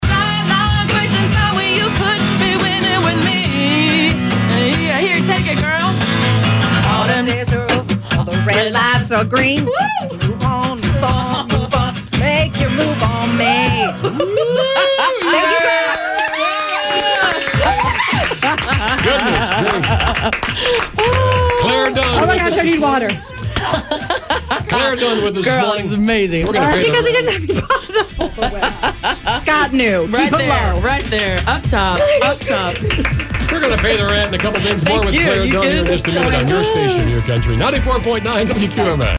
Just singing her tune Move On, oh dear!